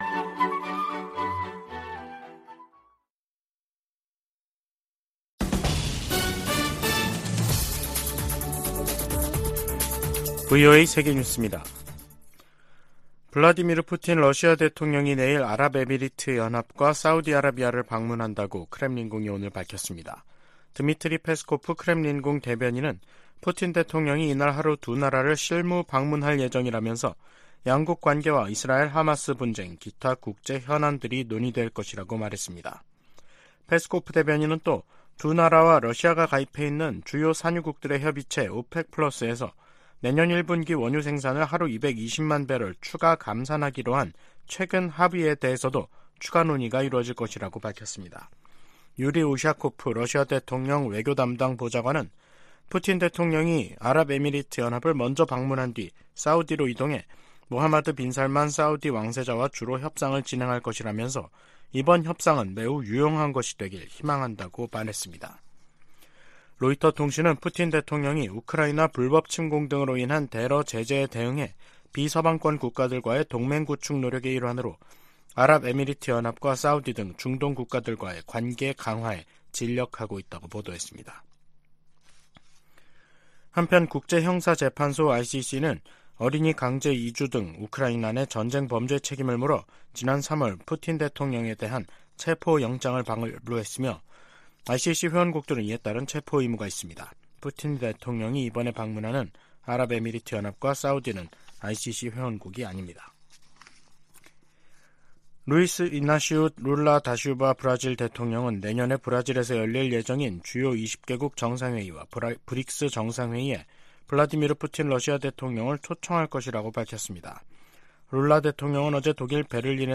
VOA 한국어 간판 뉴스 프로그램 '뉴스 투데이', 2023년 12월 5일 2부 방송입니다. 미국 정부가 남북한의 정찰 위성 발사에 대해 이중 기준을 가지고 있다는 북한의 주장을 일축했습니다. 국제 법학 전문가들도 북한이 정찰위성 발사에 대해 국제법적 정당성을 강변하는 것은 국제 규범 위반이라고 지적했습니다. 미 하원 군사위원회 부위원장이 북한 정찰위성 발사를 강력 규탄했습니다.